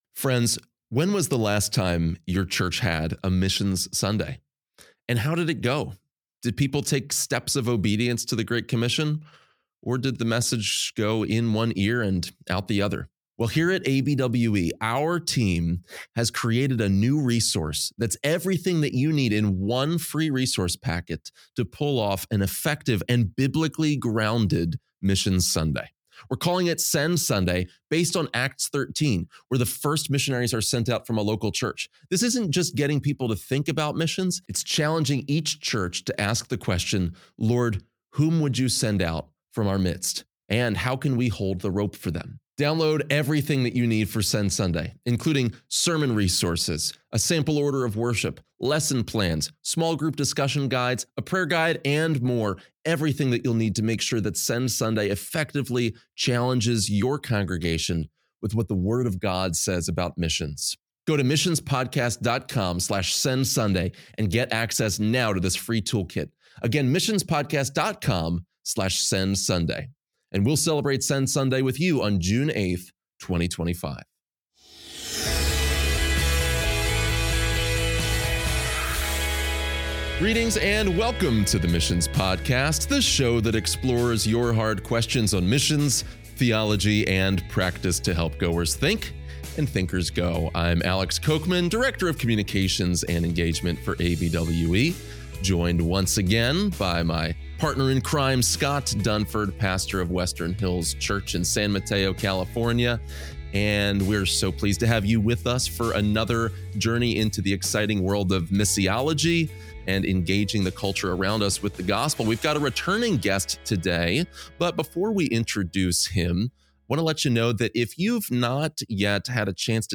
Together they discuss what the current attitude is and how the church and Christians should respond.